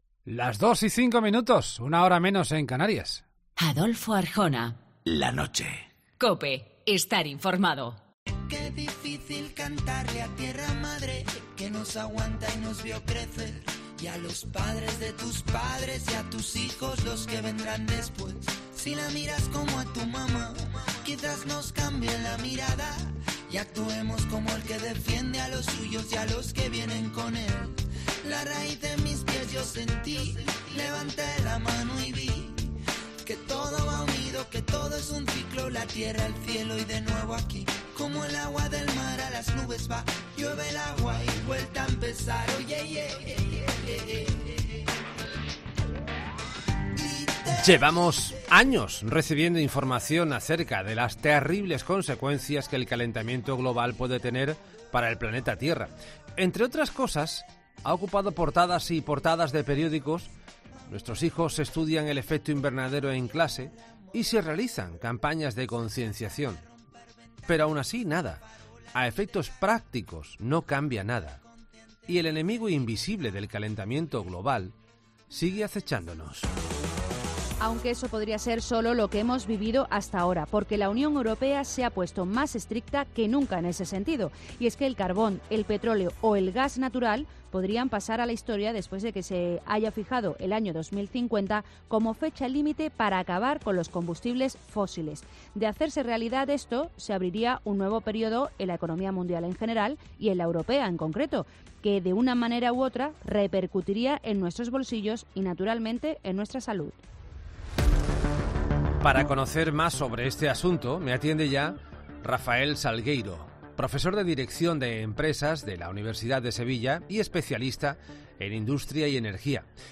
La UE ha fijado la fecha límite para acabar con los combustibles fósiles, como el carbón y el petróleo. Un experto nos explica qué cambios conllevará.